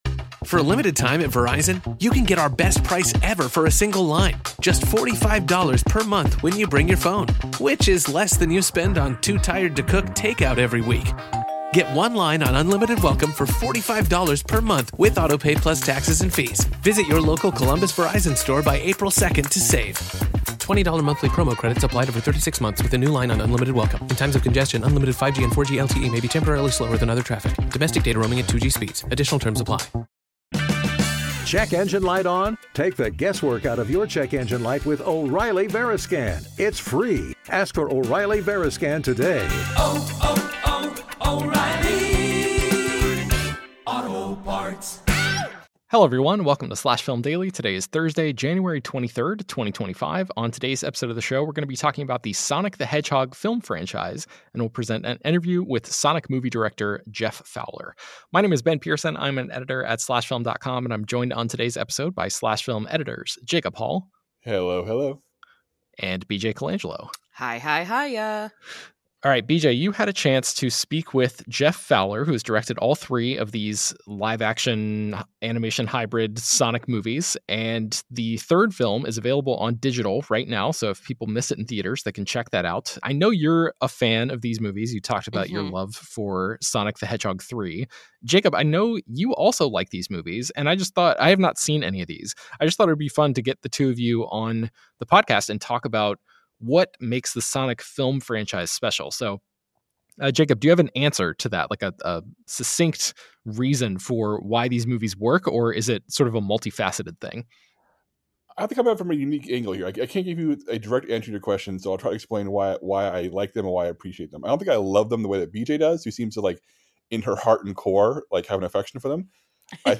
What Makes the Sonic Film Franchise Special? + Interview With Director Jeff Fowler